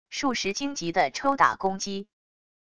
数十荆棘的抽打攻击wav音频